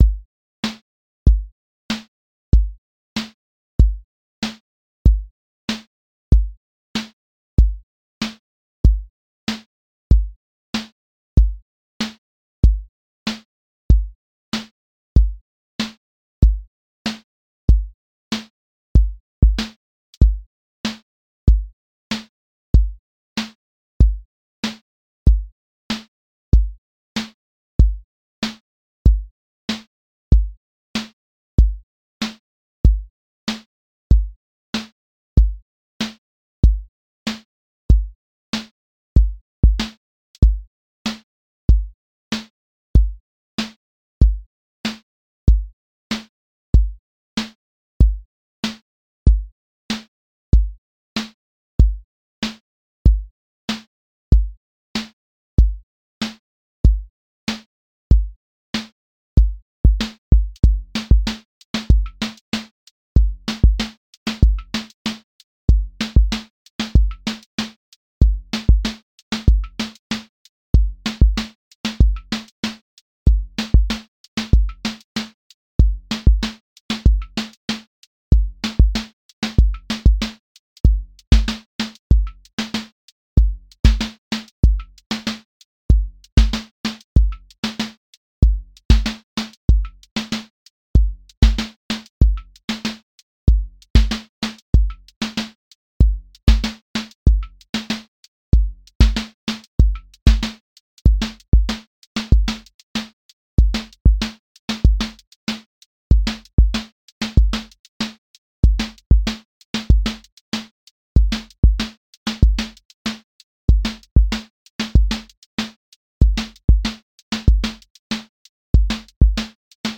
QA Listening Test boom-bap Template: boom_bap_drums_a
• voice_kick_808
• voice_snare_boom_bap
• voice_hat_rimshot
• voice_sub_pulse
• tone_warm_body
A 120-second boom bap song with recurring patterns, a lifted bridge, a grounded return, and section recombination that feels like an actual song rather than one loop